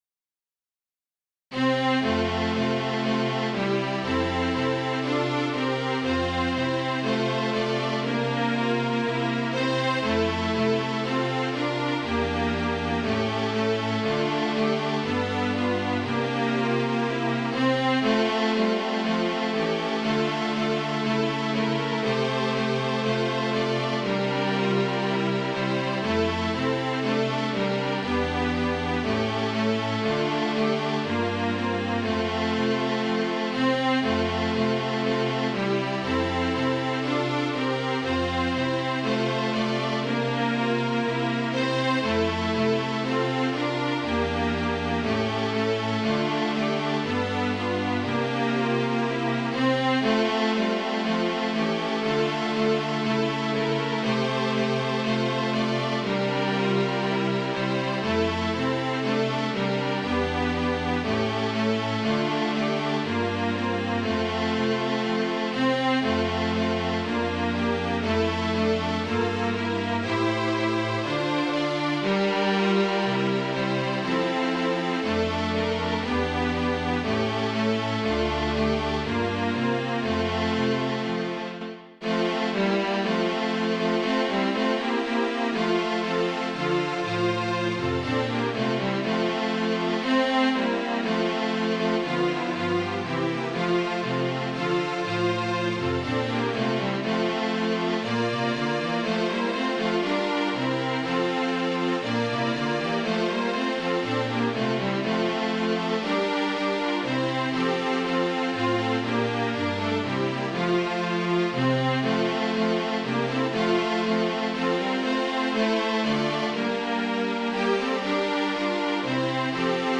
Carol Medley for Strings (
Carol Quartet.m4a